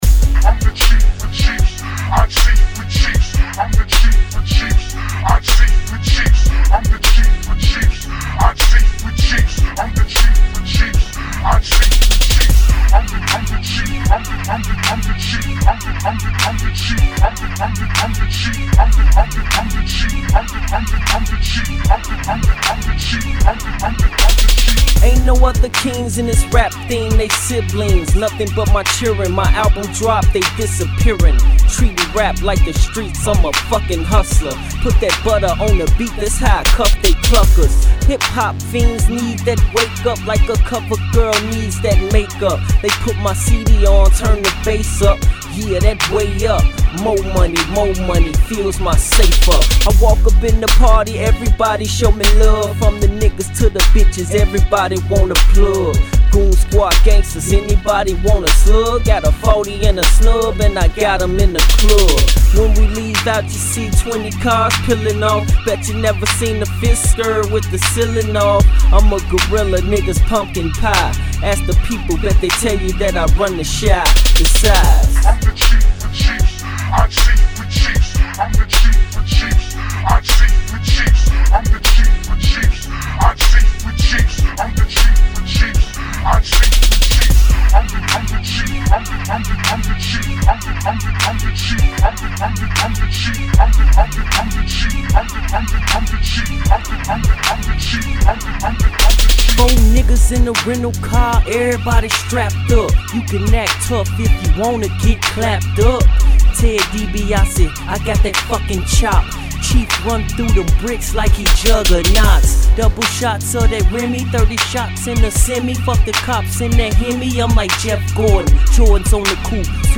tagless version